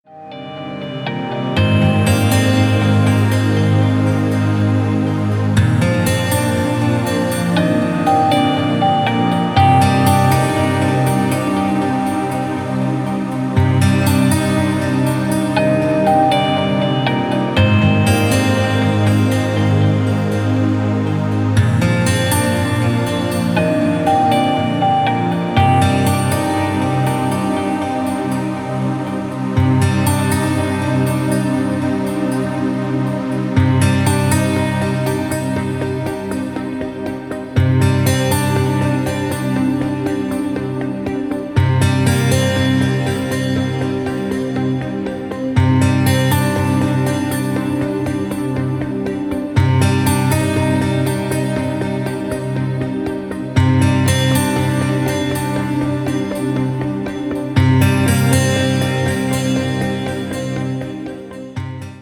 • Качество: 320, Stereo
спокойные
без слов
красивая мелодия
chillout
нежные